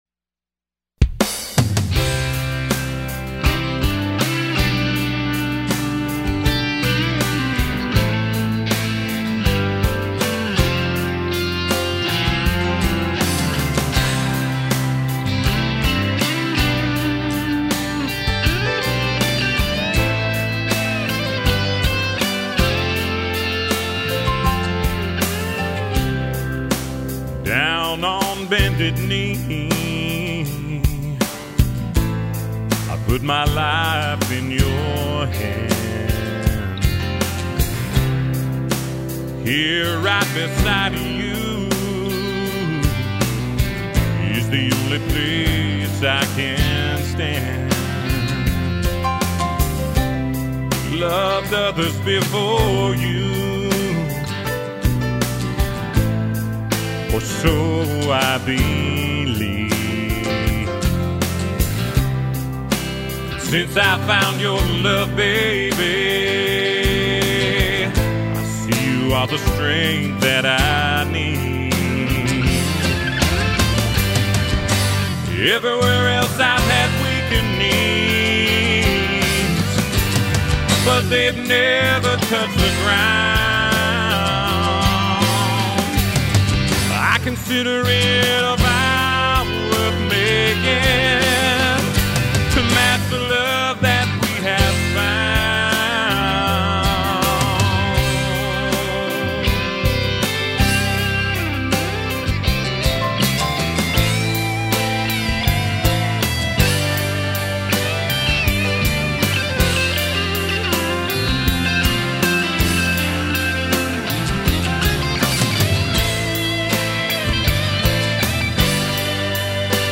Listen to our demo recording of Bended Knee, we think it would make a good wedding song.